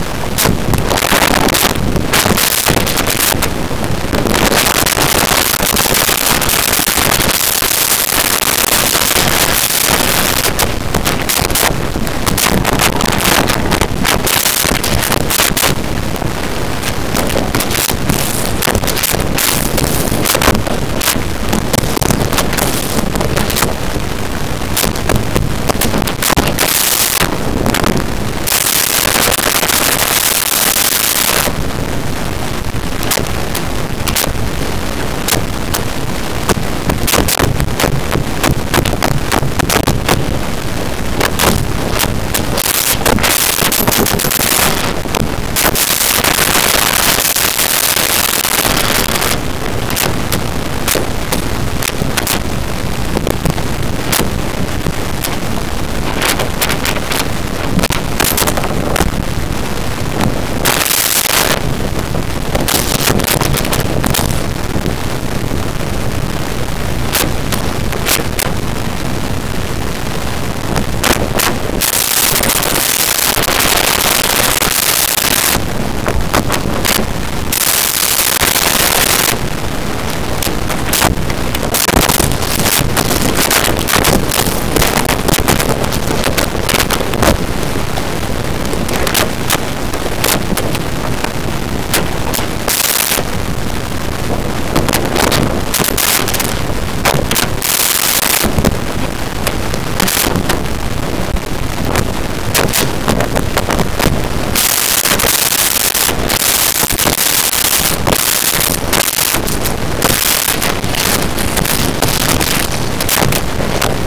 Card Movement With Coins
Card Movement With Coins.wav